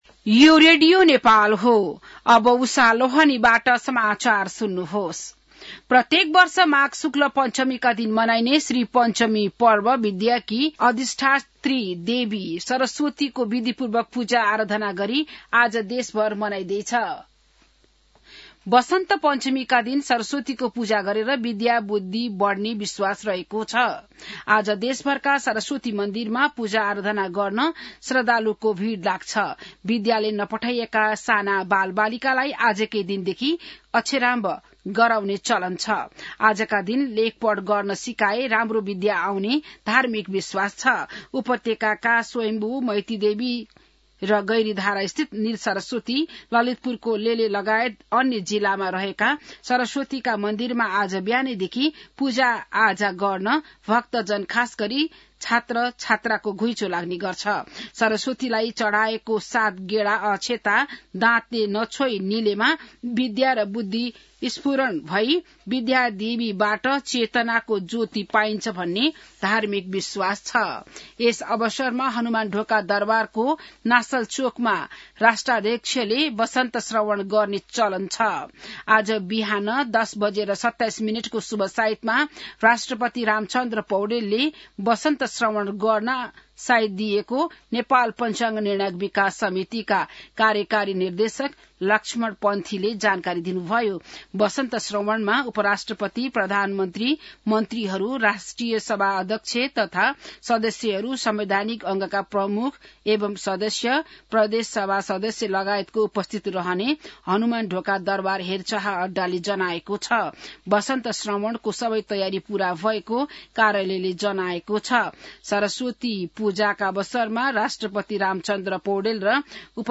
बिहान १० बजेको नेपाली समाचार : ९ माघ , २०८२